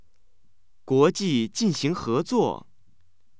happy